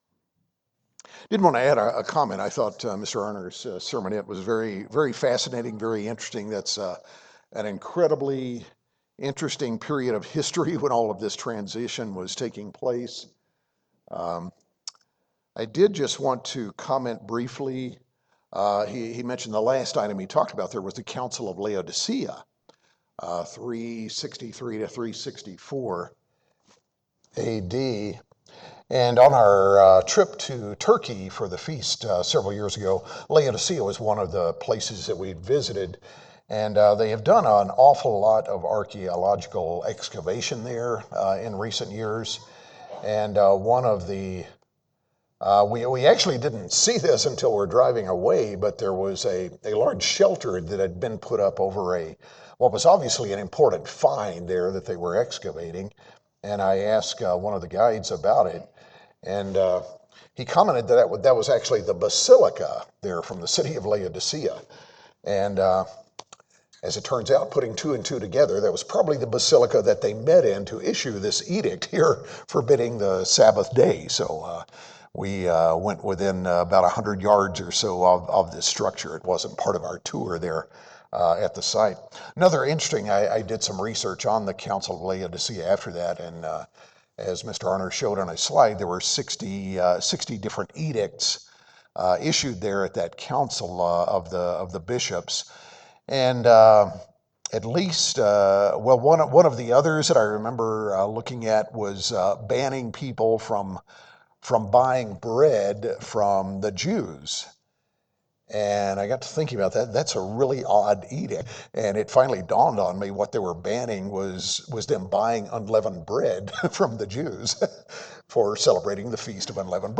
Passover and the Feast of Unleavened Bread will soon be on us, reminding us of Israel’s miraculous Exodus from Egypt. In this second part of a sermon series, we’ll examine parts of the story in more detail to see what lessons it holds for Christians today.